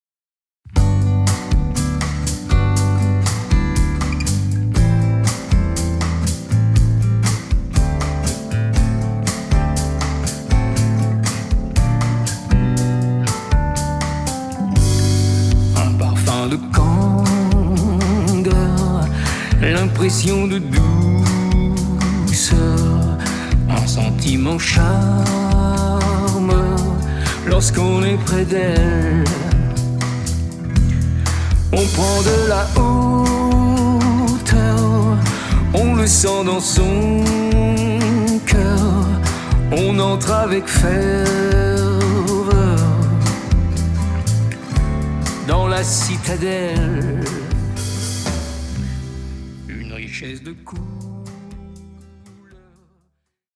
exprimées en ballades, jazz, bossa-novas, pop